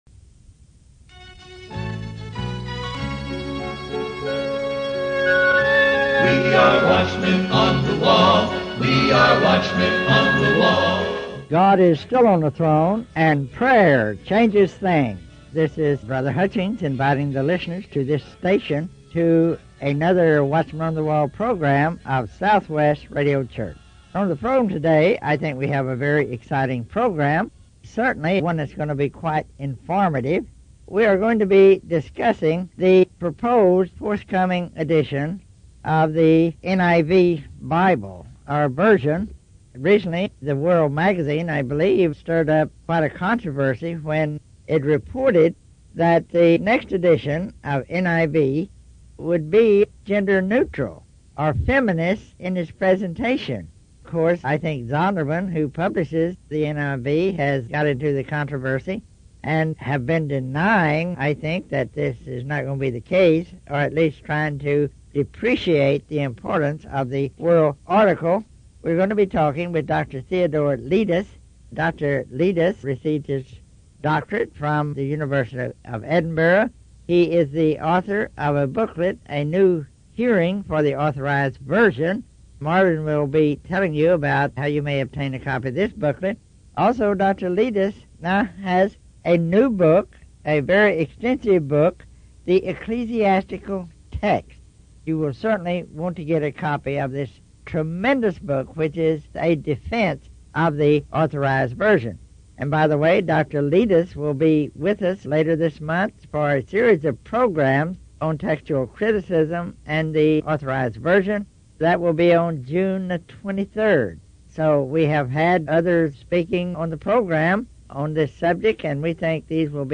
It involved capturing the 6 cassette tape series (see picture) to a digital (mp3) format for PC use.
aired on Southwest Radio Church, Oklahoma City, OK.